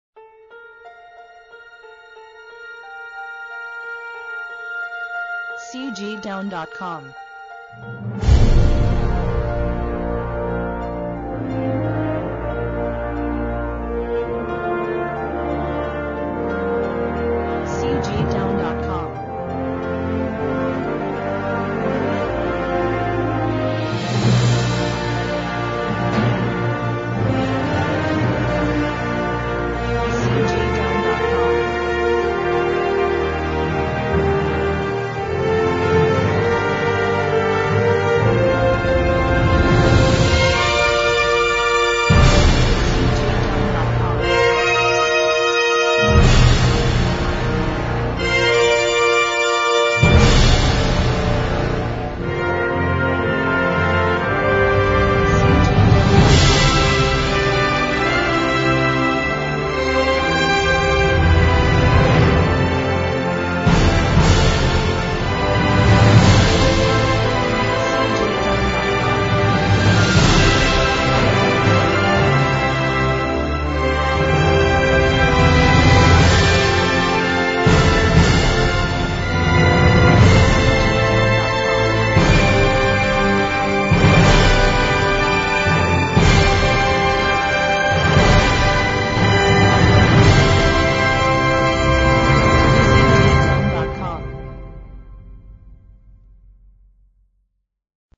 1970-01-01 辉煌感动